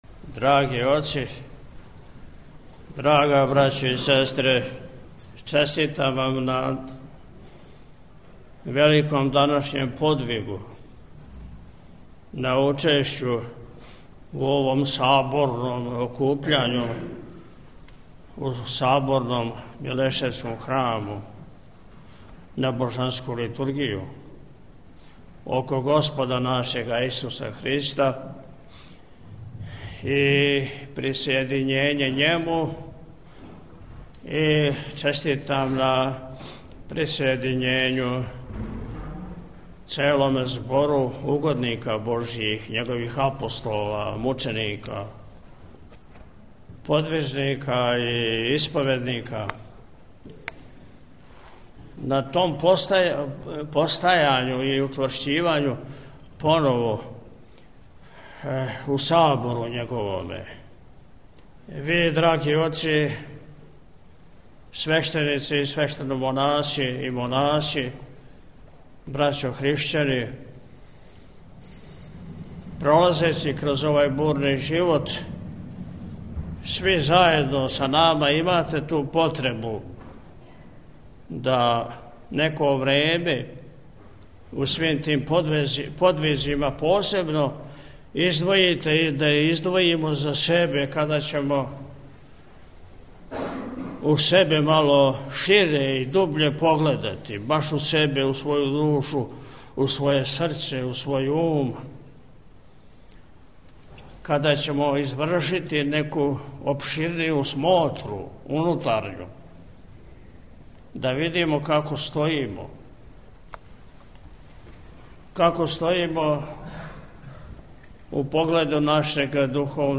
Mileseva-beseda-Ispovest.mp3